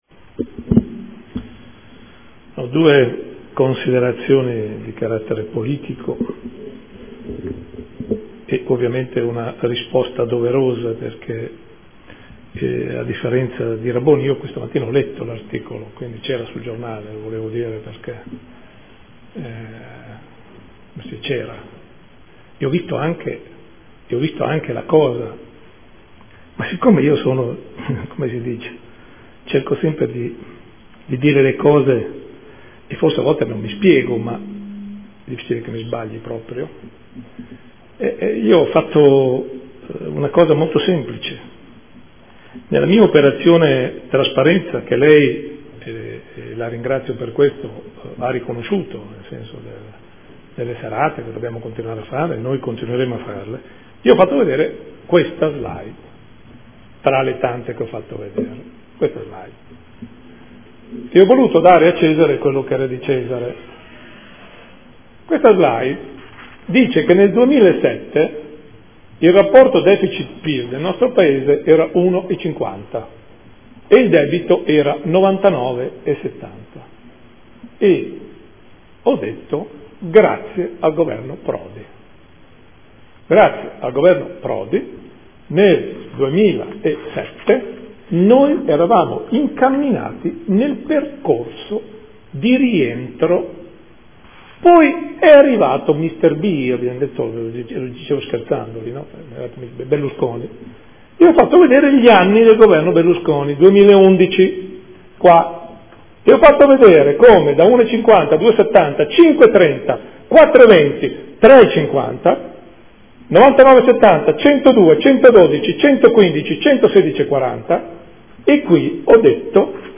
Seduta del 27/11/2014. Mozione presentata dai consiglieri Cugusi (SEL), Querzè, Campana (Per me Modena), Bortolotti (M5S) avente per oggetto: “Stop TTIP” e Mozione presentata in corso di seduta.